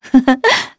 Veronica-Vox-Laugh.wav